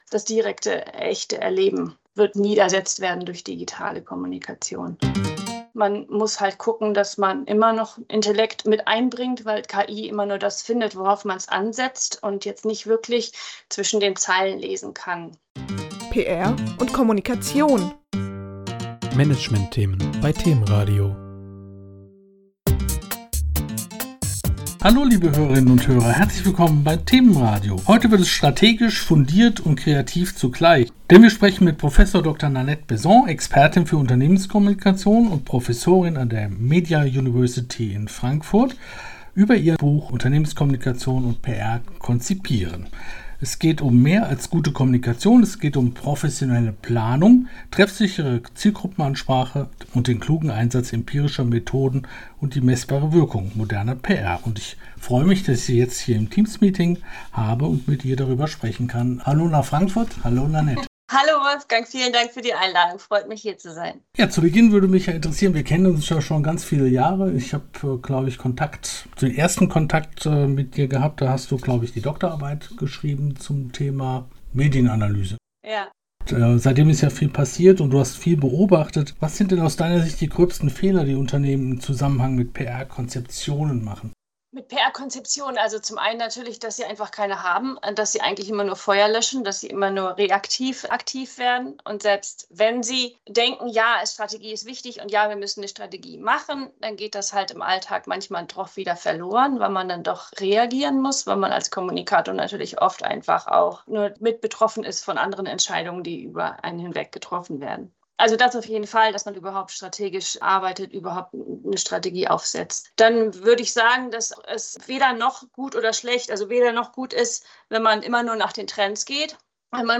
Im Interview: